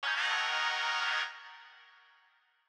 GFunk II.wav